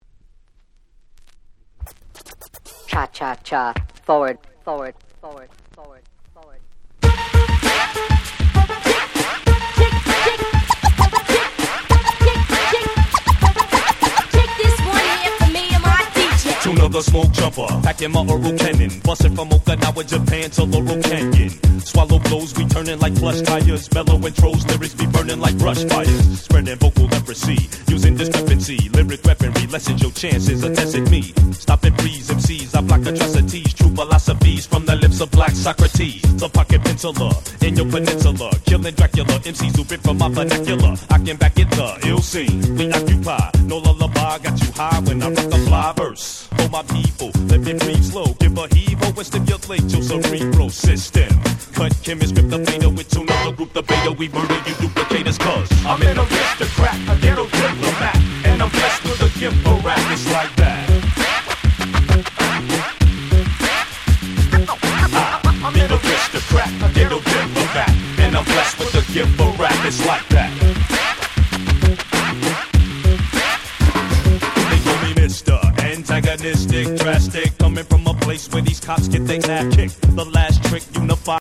99' Underground Hip Hop Classics !!